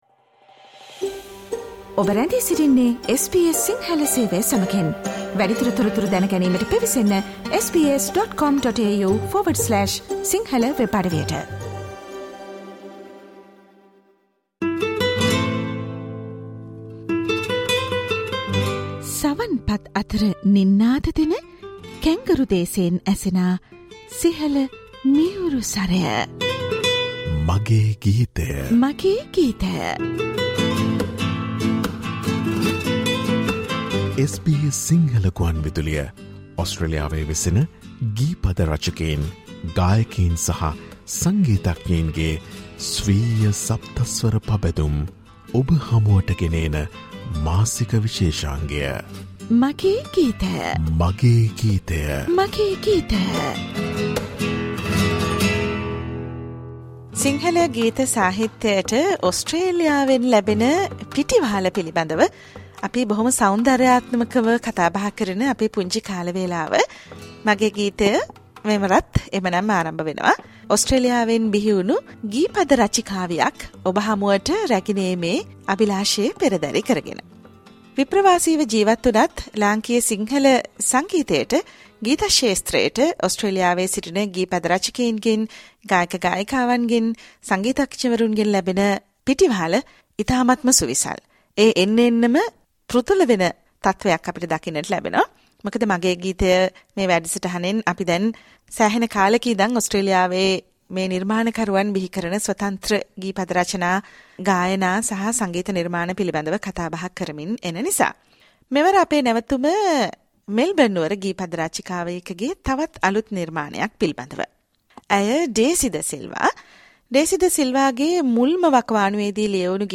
SBS Sinhala ‘My Song’ monthly musical program - Introducing lyricists, vocalists, musicians, and the new Sinhala songs from Sri Lankans who live in Australia.